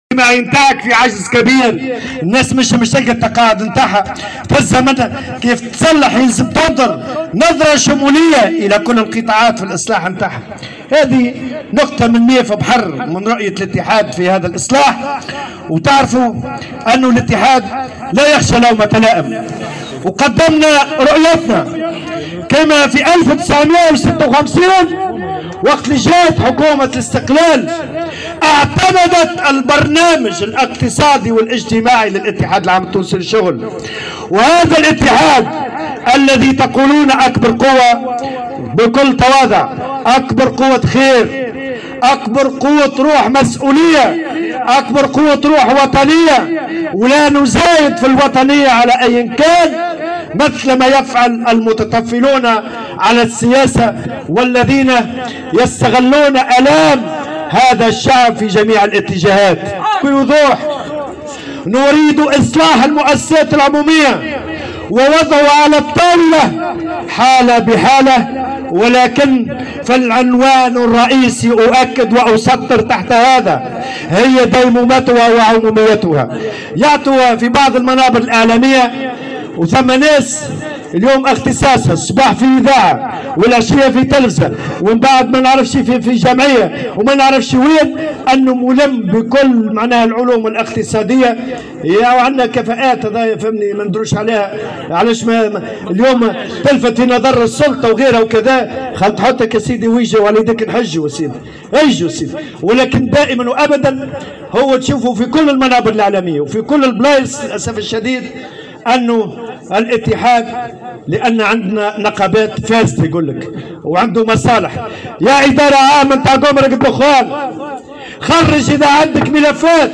وانتقد الطبوبي في كلمة ألقاها خلال تجمع عمالي، اليوم الخميس، بمقر شركة التبغ والوقيد، بعض الأطراف التي لا تترك مناسبة للظهور عبر وسائل الإعلام، إلا وقامت بمهاجمة المنتظمة الشغيلة واتهامها بالفساد، داعيا أي شخص يملك ملفا ضد أحد النقابيين إلى تقديمه إلى القضاء.